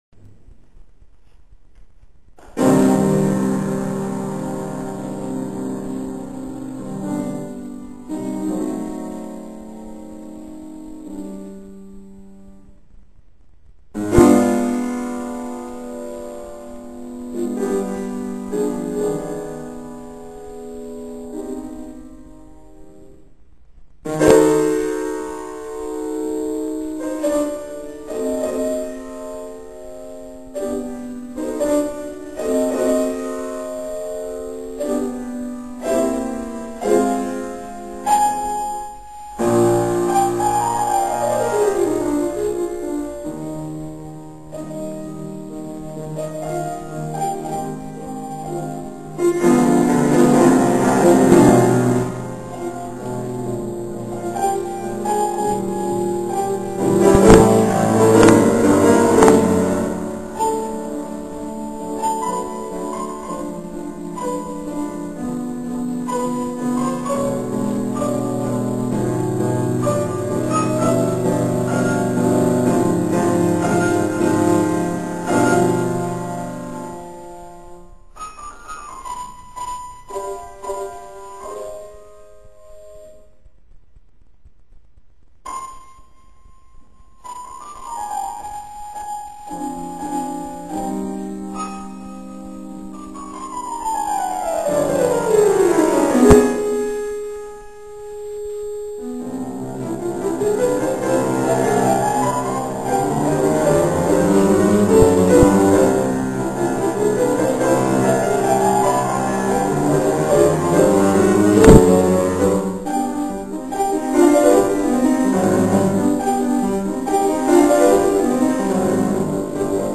19. komorní koncert na radnici v Modřicích
- ukázkové amatérské nahrávky, v ročence CD Modřice 2006 doplněno: